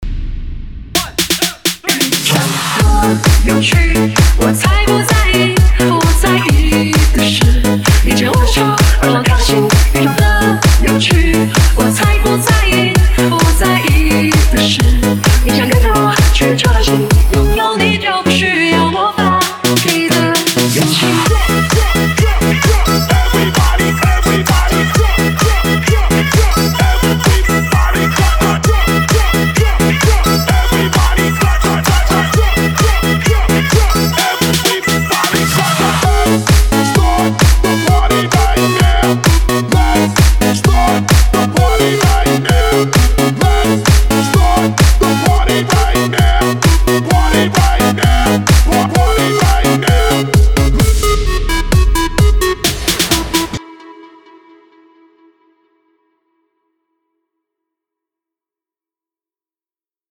5天前 DJ音乐工程 · Electro House 2 推广